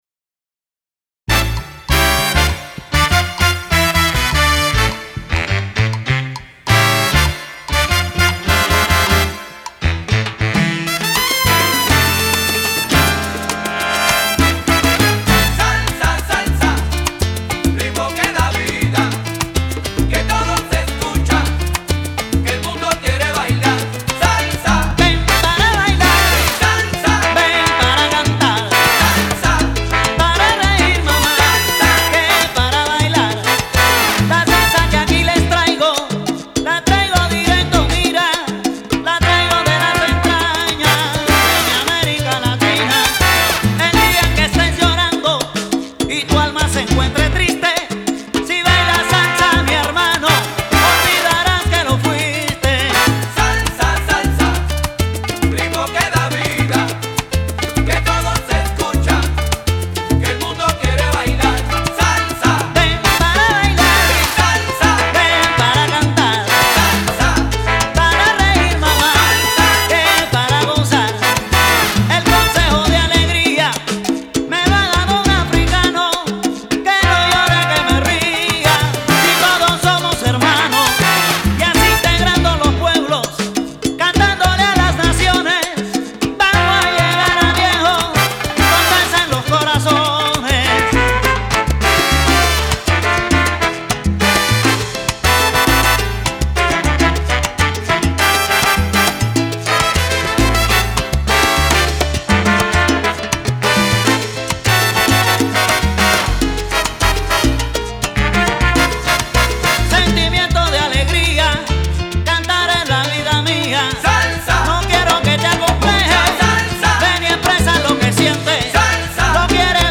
01---Salsa-Cool-----.mp3